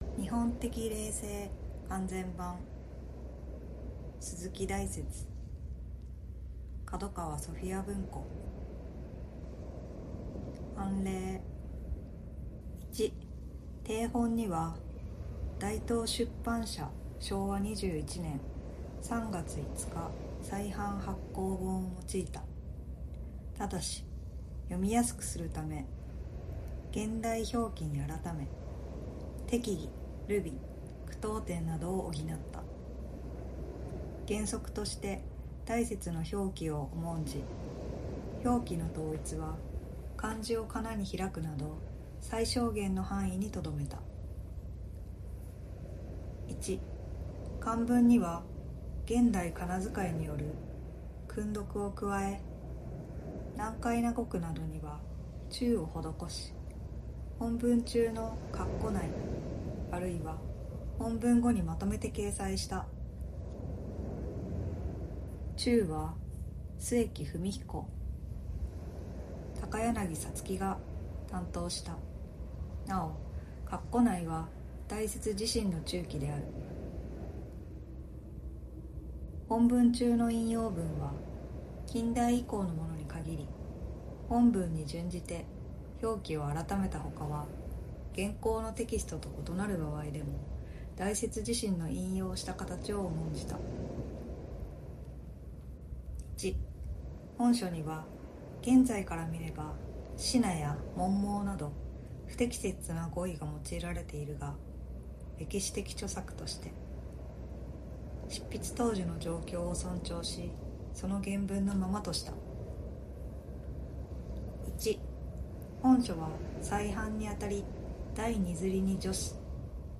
『日本的霊性』①作：鈴木大拙 朗読 読書と同時に作業用BGMや睡眠導入 おやすみ前 教養にも